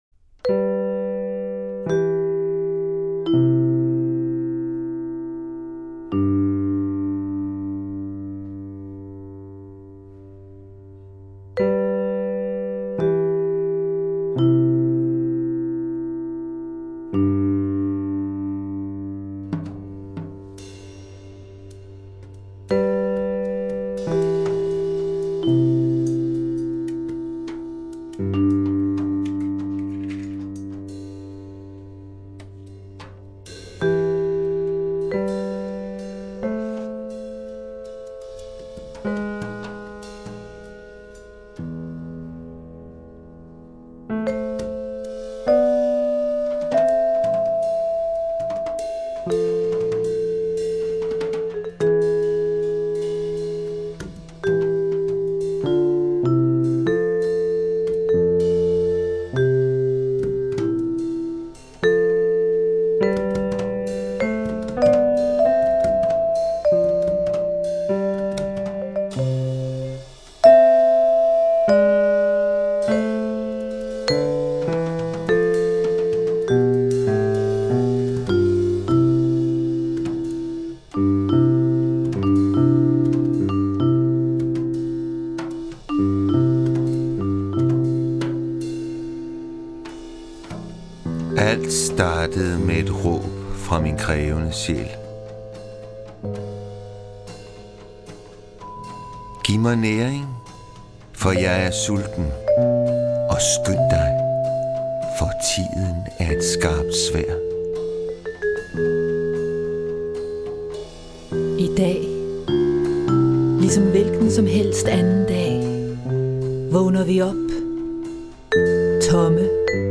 Studiosessions - 2007